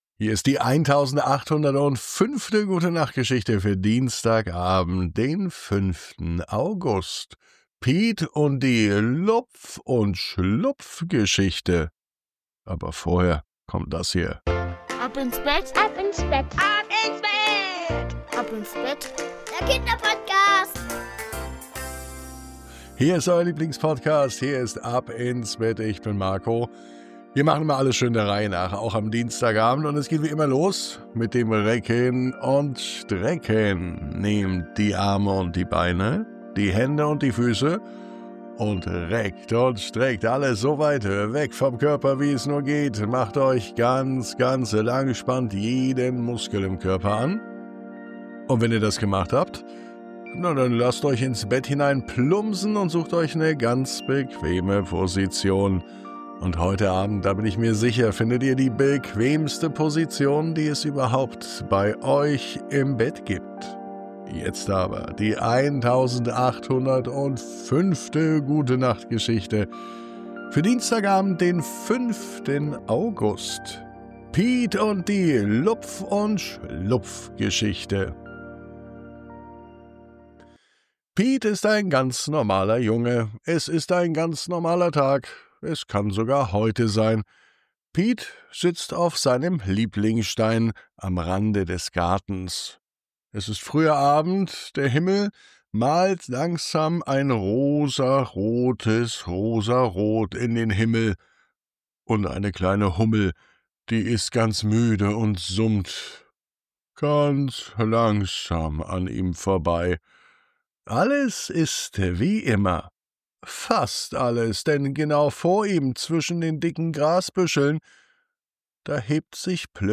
Diese neue Gute Nacht Geschichte ist perfekt zum Einschlafen und Träumen – liebevoll erzählt, ruhig und fantasievoll.